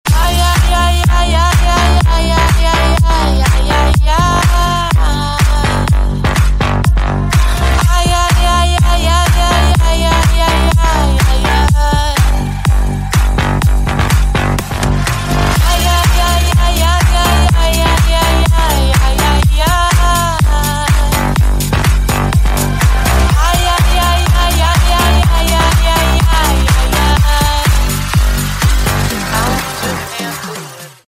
Клубные Рингтоны » # Громкие Рингтоны С Басами
Рингтоны Ремиксы » # Танцевальные Рингтоны